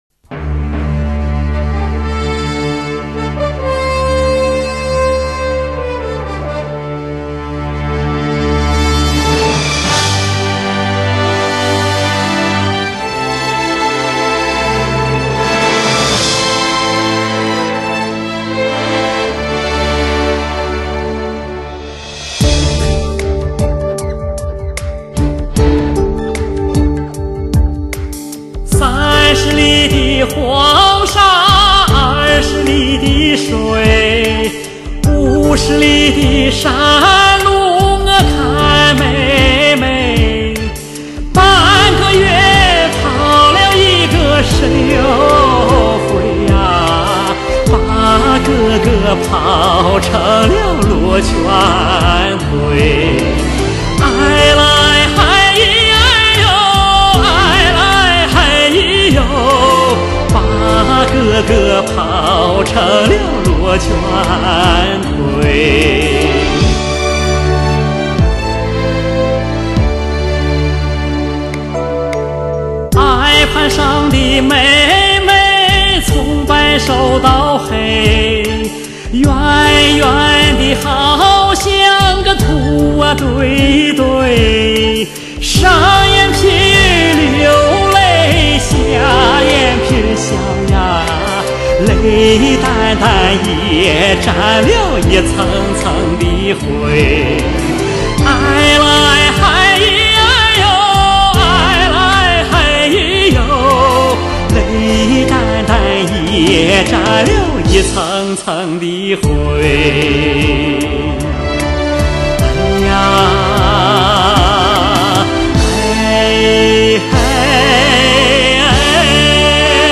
[华语怀旧]